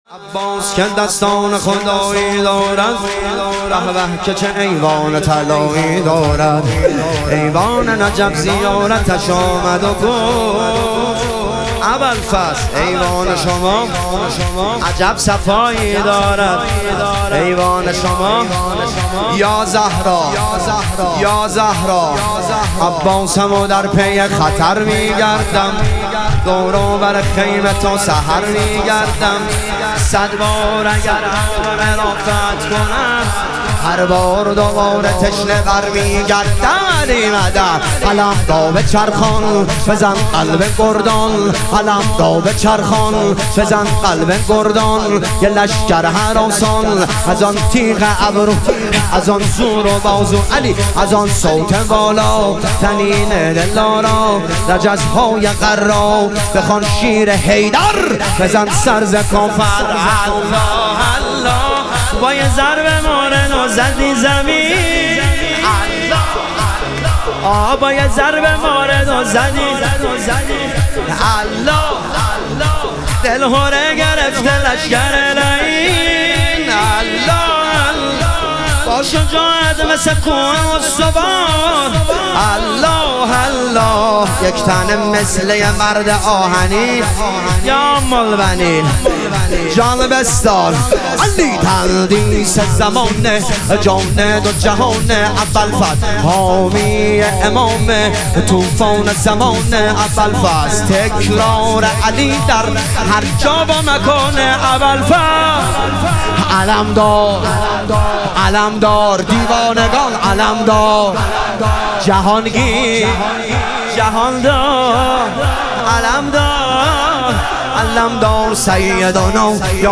شور
شب شهادت امام هادی علیه السلام